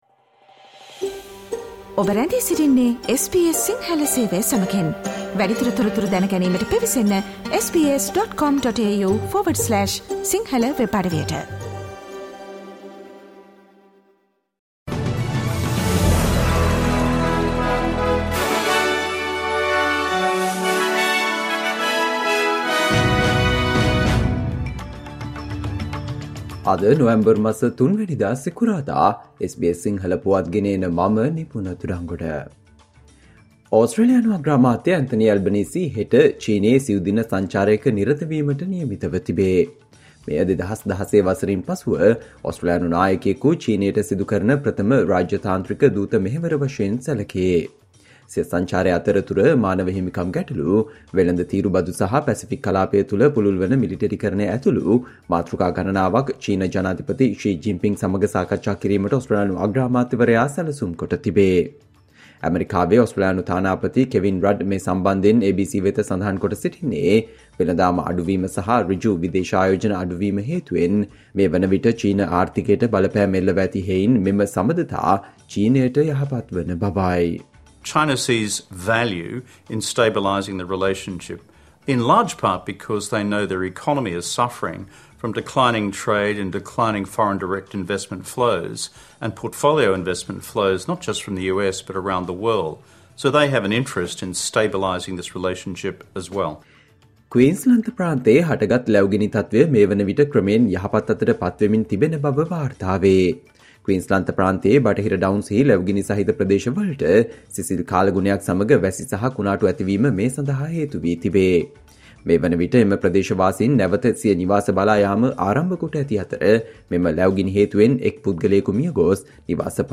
Australia news in Sinhala, foreign and sports news in brief - listen Sinhala Radio News Flash on Friday 03 November 2023.